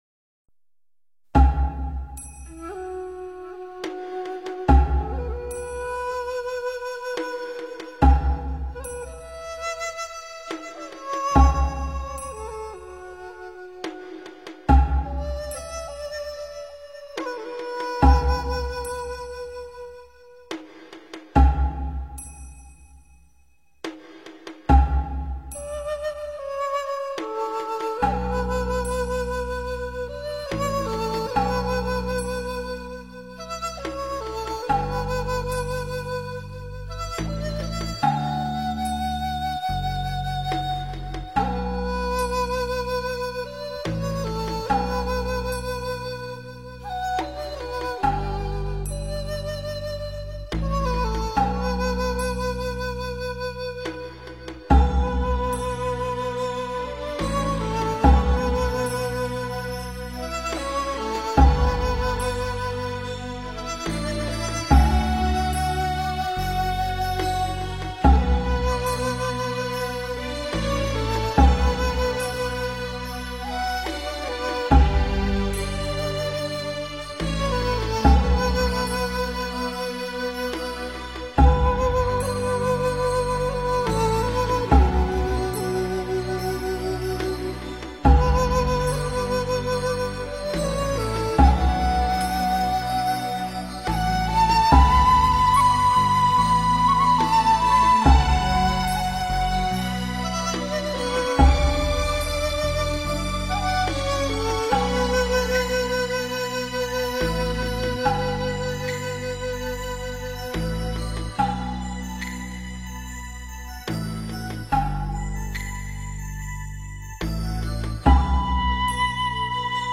佛音 诵经 佛教音乐 返回列表 上一篇： 楞严咒 下一篇： 药师佛赞 相关文章 弗意图--何训田 弗意图--何训田...